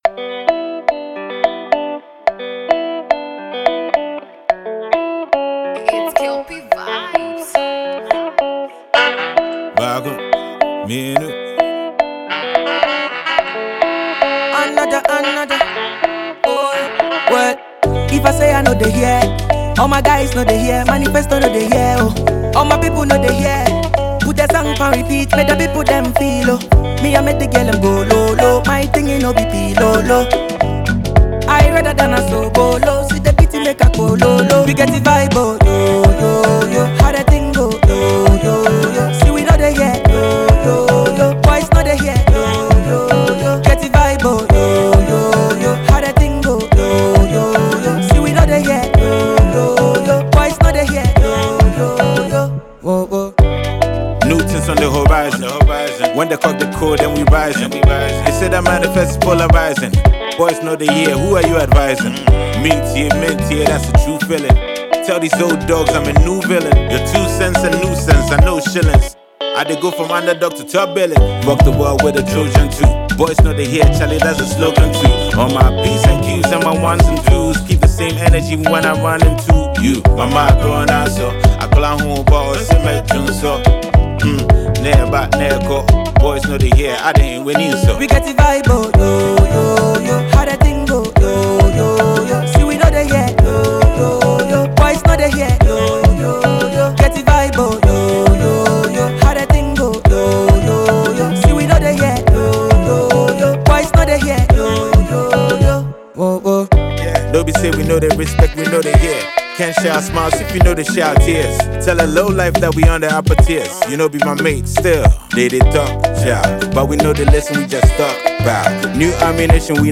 One of Ghana’s best rappers
Afrobeat Badman
groovy new single